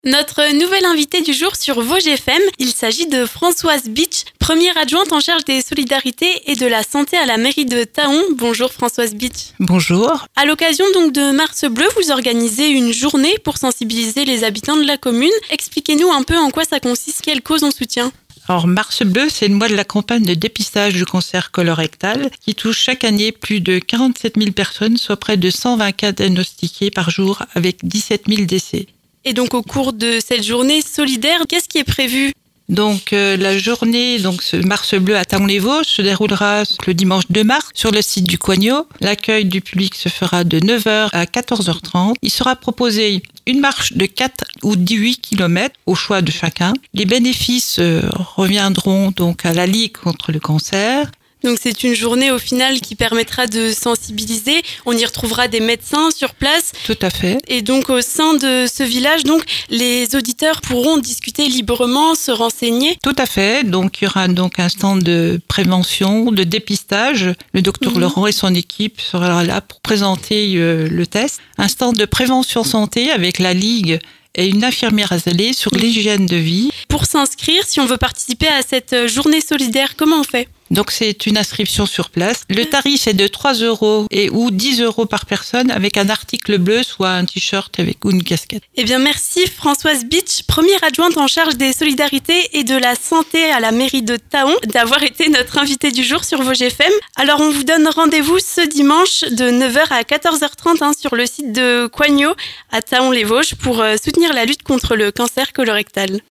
L'invité du jour
A cette occasion, Françoise Bitch, adjointe à la municipalité, est notre invitée du jour sur Vosges FM.